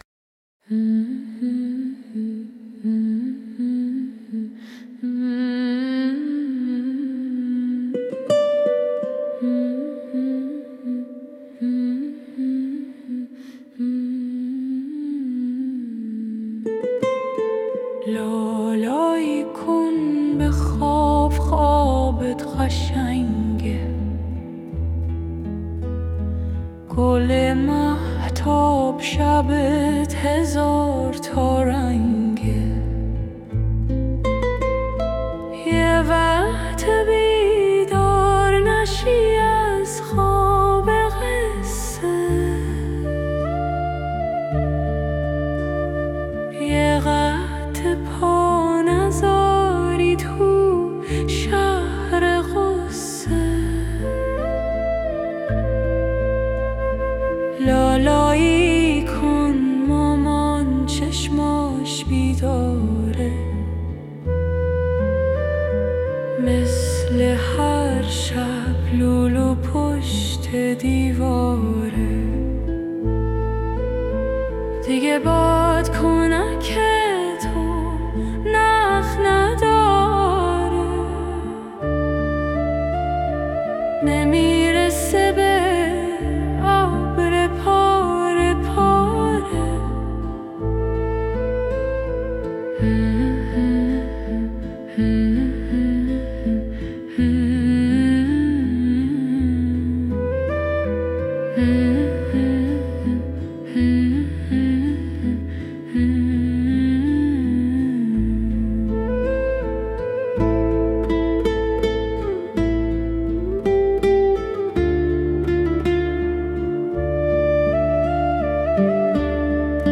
Style: Persian lullaby, relaxing🎧